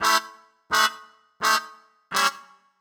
GS_MuteHorn_85-A.wav